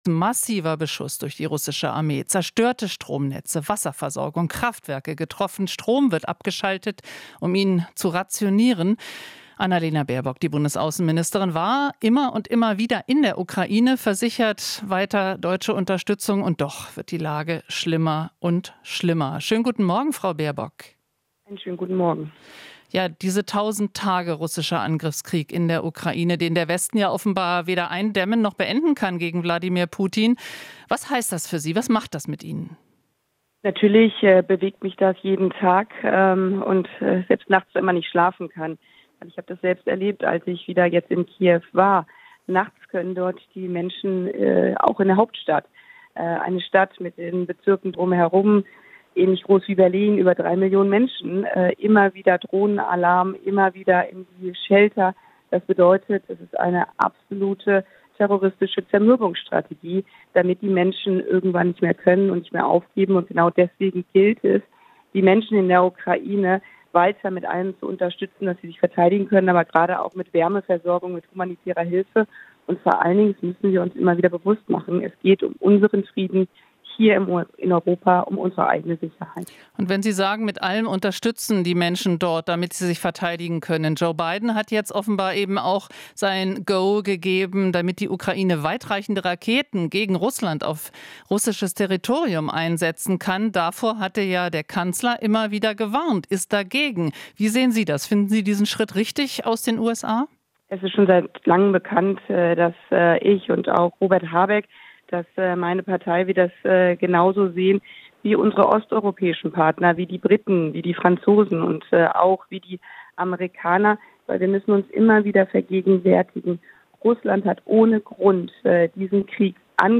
Interview - Baerbock (Grüne): Worte allein helfen der Ukraine nicht